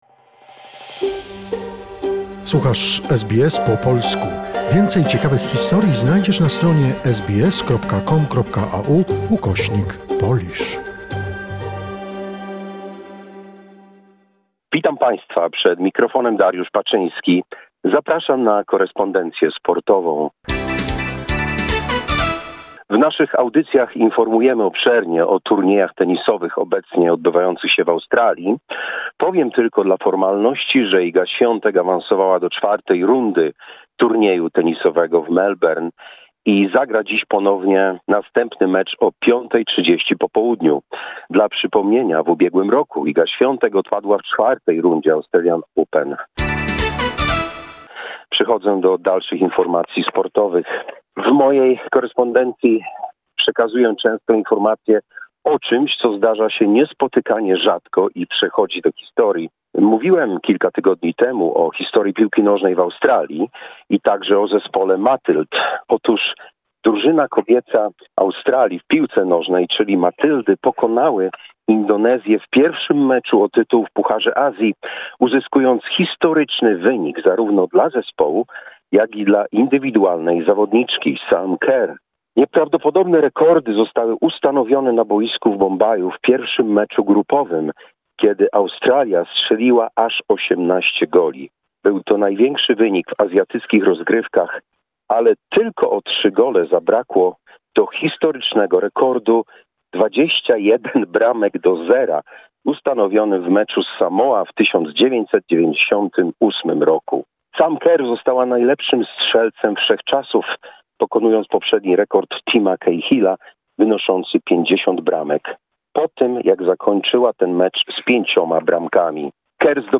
presents Monday sports summary of the week.